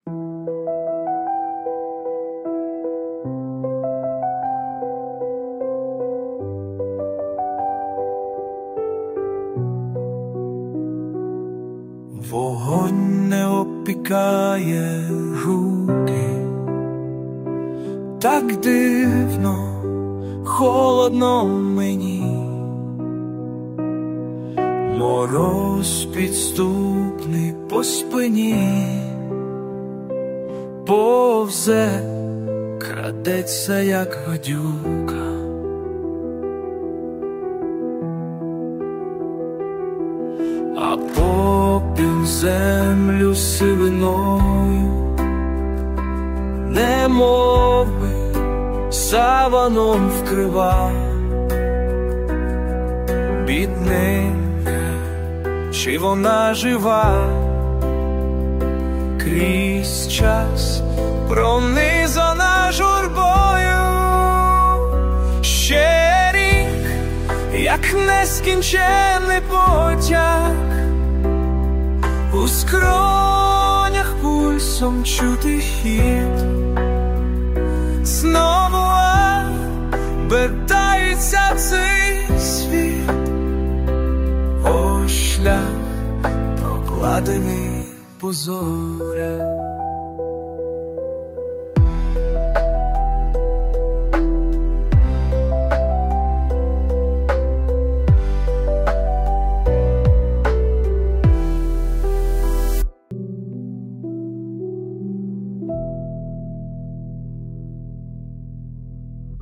Текст - автора, втілення пісні - ШІ.
ТИП: Пісня
СТИЛЬОВІ ЖАНРИ: Ліричний